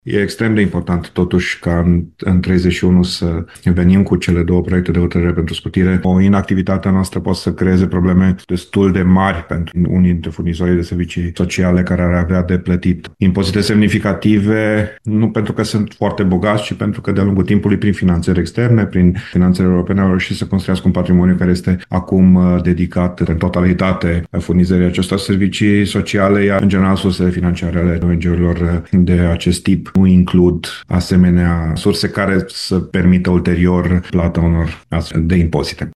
Noua lege permite acordarea unor scutiri de impozit pentru clădirile istorice aflate în reabilitare și pentru ONG-urile care oferă servicii sociale. Pentru aplicarea acestor scutiri, Consiliul Local se va întruni în ședință miercuri, pentru a adopta alte hotărâri, spune consilierul local, Dan Diaconu.